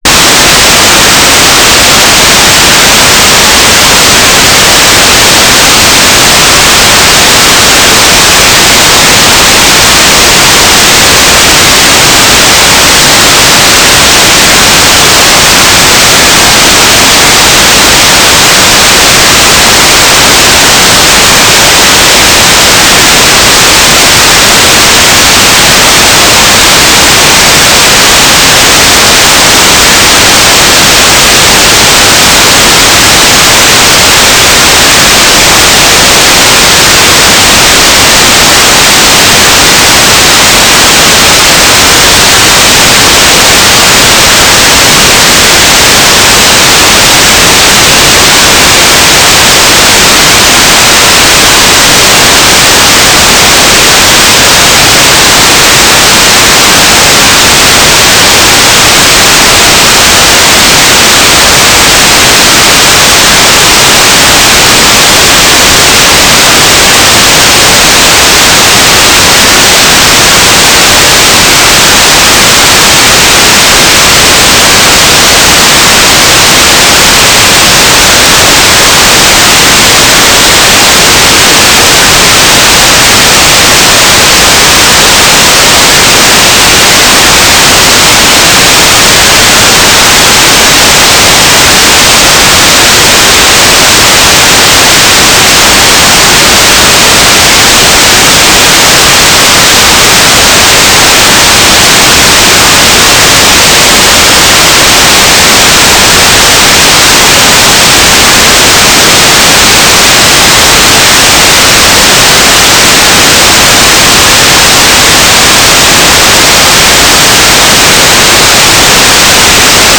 "transmitter_description": "Mode U - GMSK9k6 - AX.25",